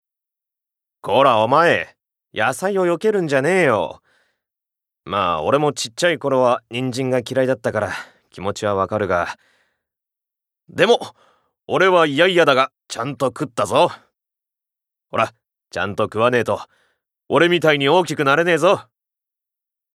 Voice Sample
ボイスサンプル
セリフ３